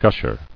[gush·er]